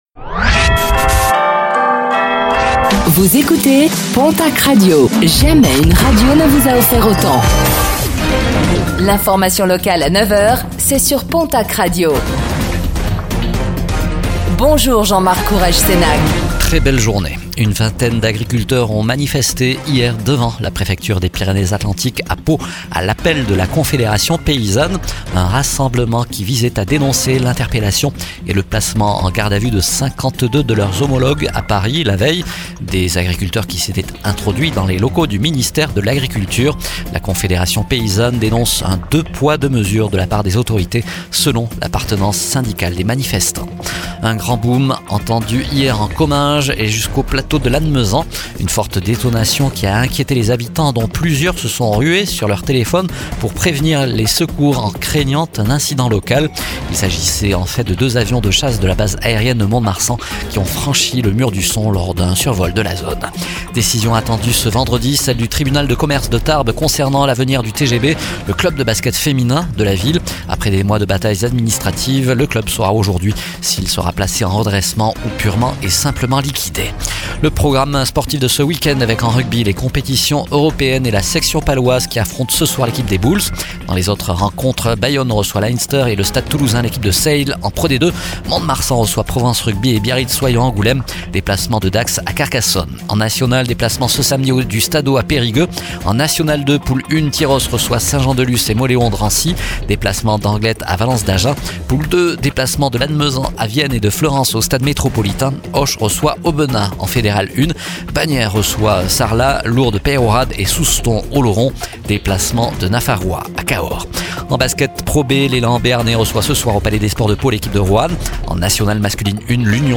09:05 Écouter le podcast Télécharger le podcast Réécoutez le flash d'information locale de ce vendredi 16 janvier 2026